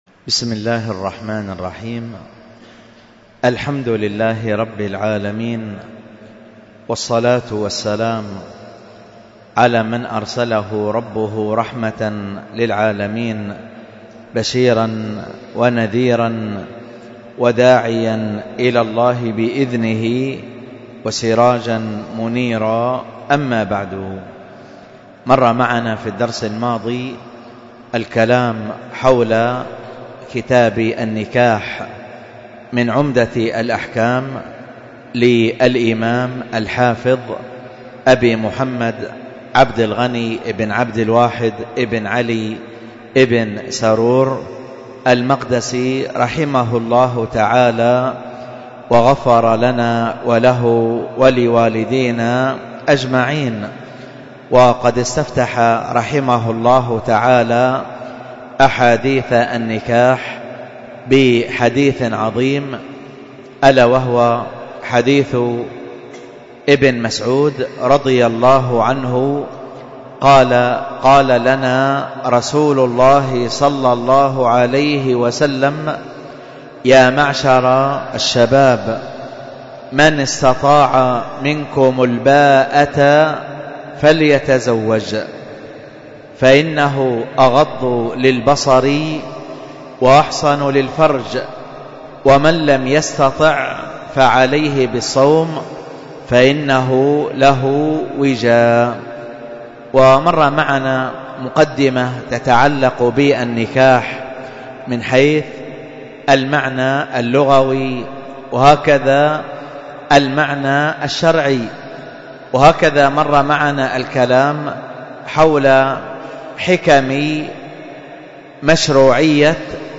الدرس في كتاب النكاح 4